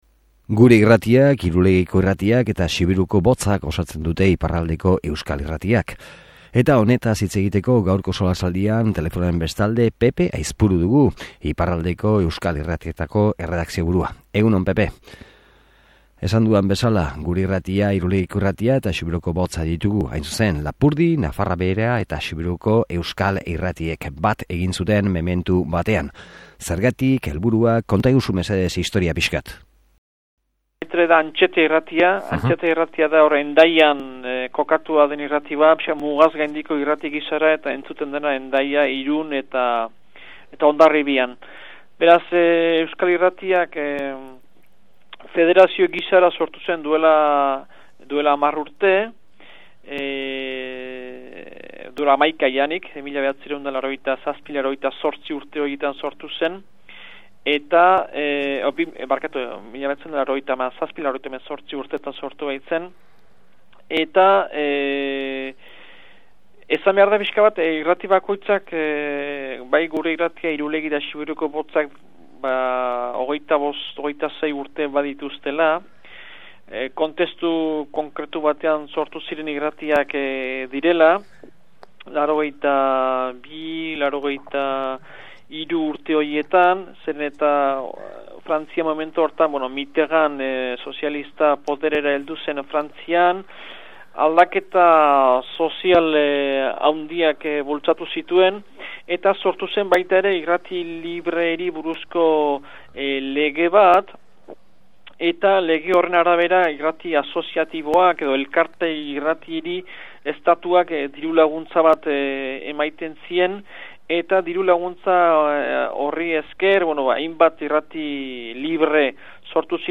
SOLASALDIA: Iparraldeko Euskal Irratiak